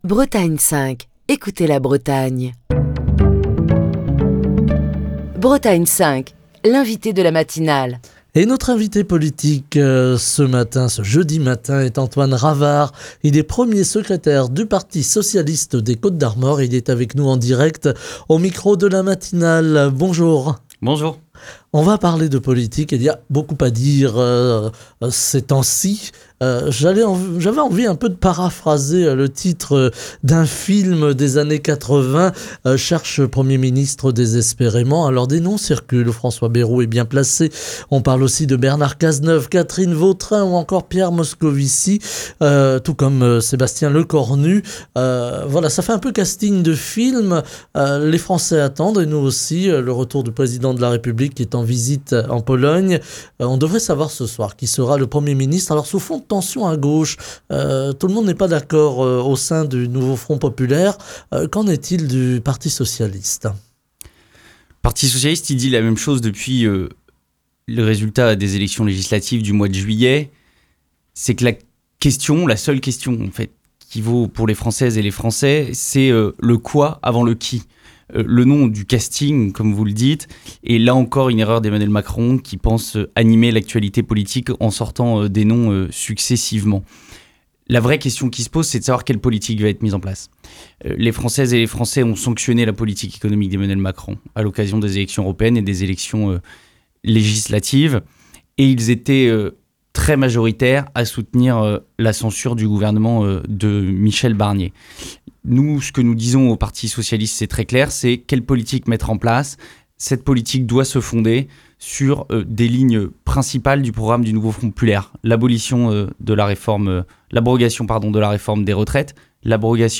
Émission du 12 décembre 2024.